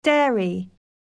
미국 [dέəri] 듣기반복듣기 영국 [dέəri] 듣기